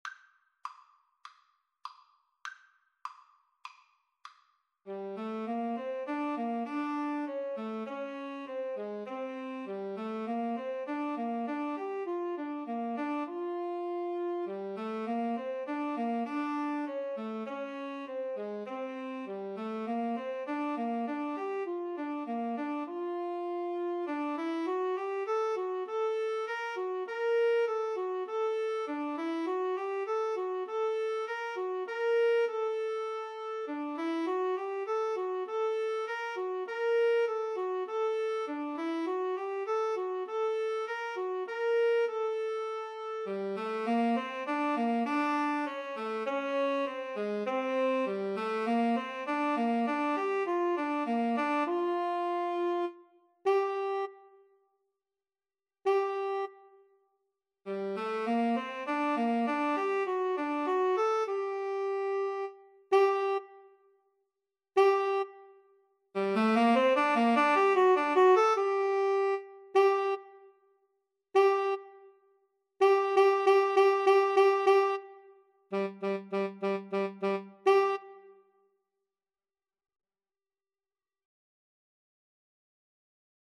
Tenor Sax 1Tenor Sax 2
Classical (View more Classical Tenor Sax Duet Music)